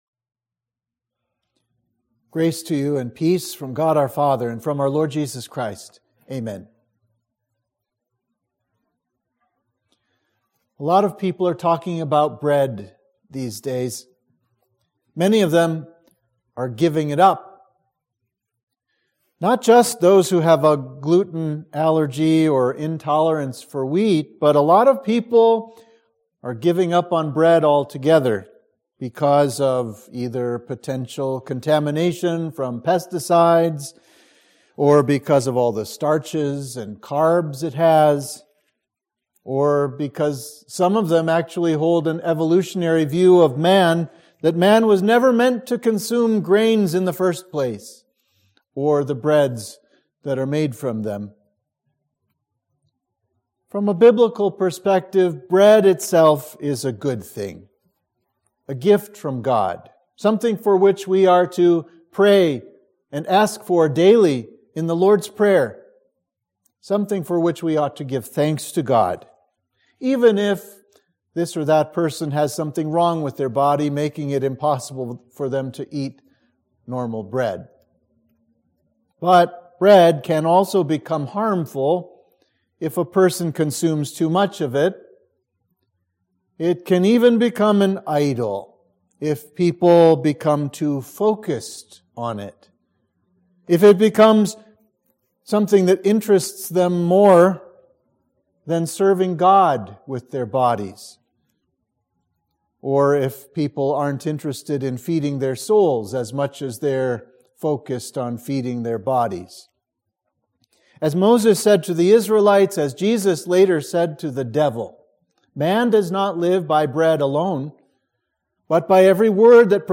Sermon for Laetare – Lent 4